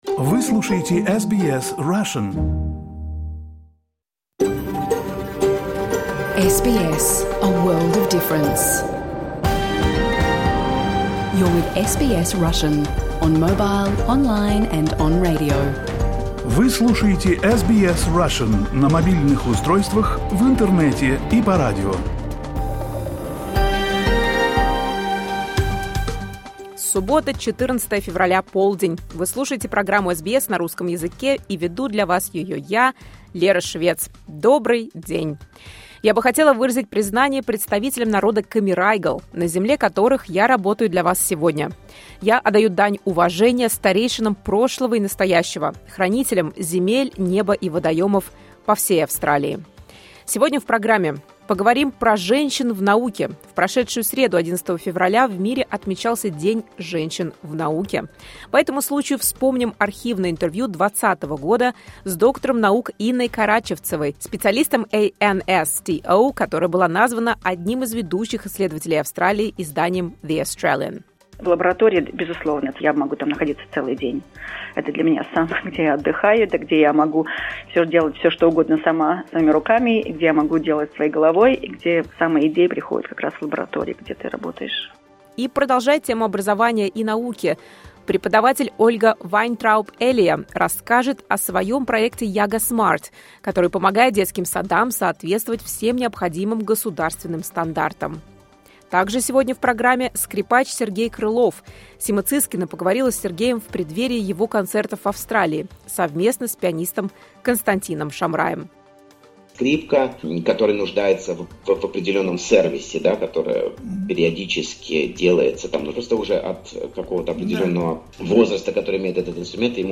Программу SBS Russian в прямом эфире можно слушать по радио, на нашем сайте и в приложении SBS Audio.
Больше историй, интервью и новостей от SBS Russian доступно здесь.